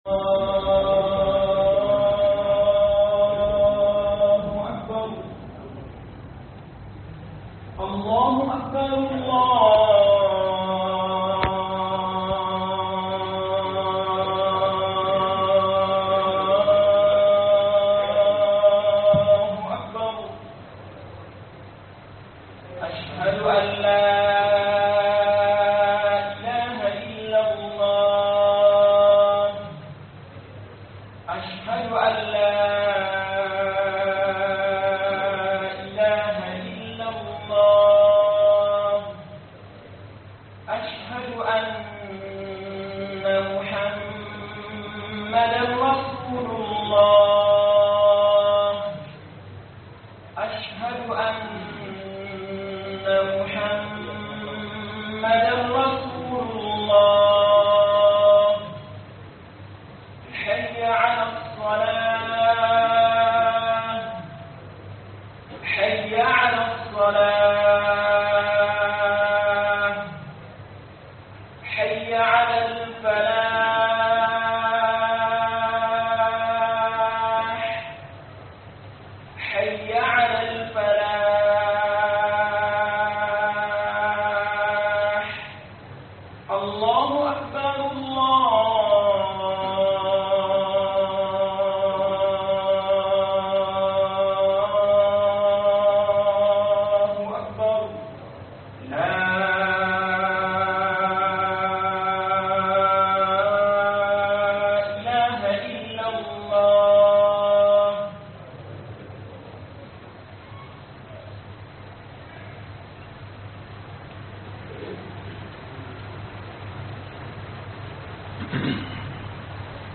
Kalmomi Biyar (5) Daga Manzon Allah (SAW) - HUDUBA